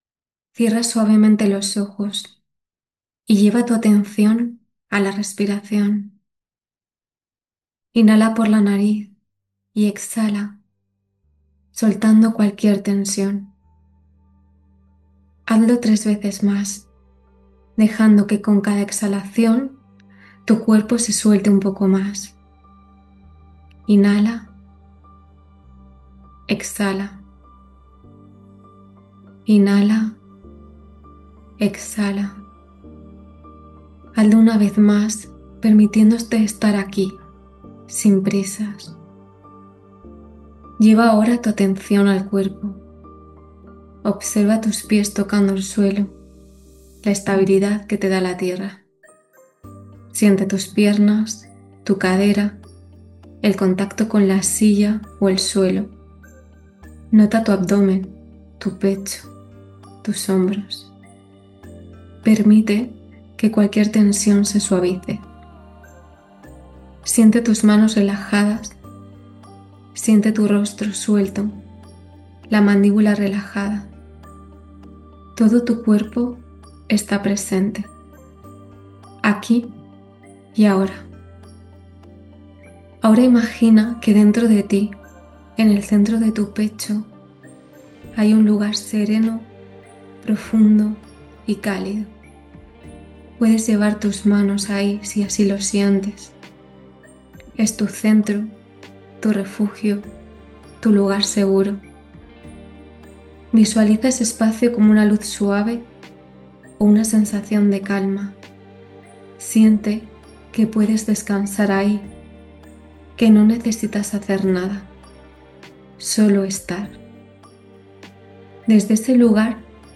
Meditación
Meditacion-completa-con-musica.mp3